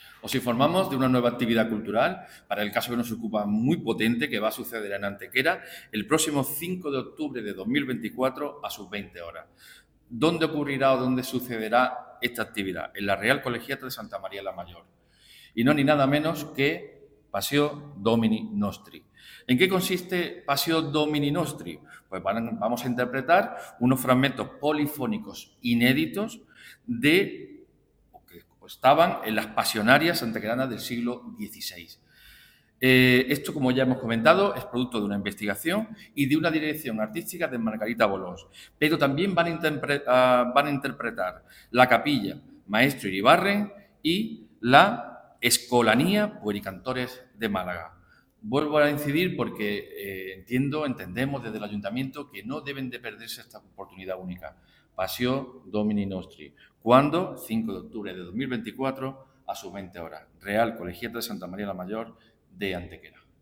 El concejal delegado de Cultura y Patrimonio Histórico, José Medina Galeote, informa del próximo desarrollo de un concierto que ofrecerá la representación musical de fragmentos polifónicos inéditos en los pasionarios antequeranos del siglo XVI, libros de canto que contienen la historia de la pasión de Jesucristo.
Cortes de voz